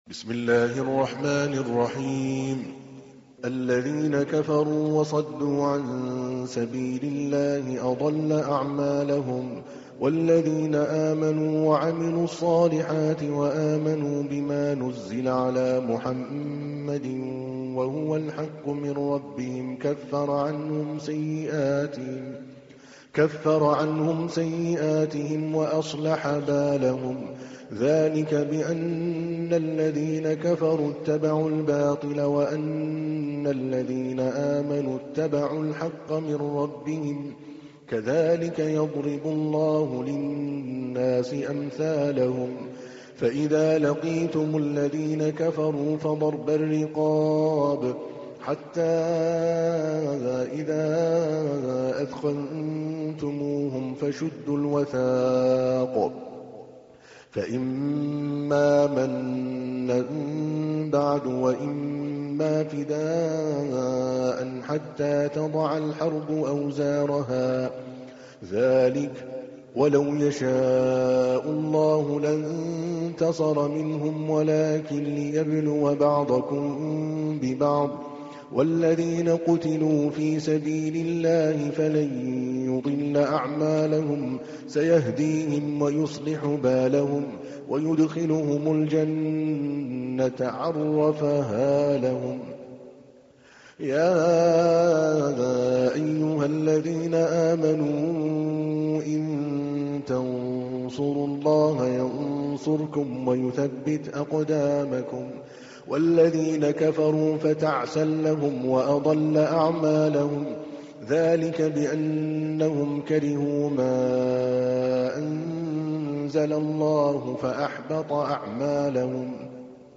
تحميل : 47. سورة محمد / القارئ عادل الكلباني / القرآن الكريم / موقع يا حسين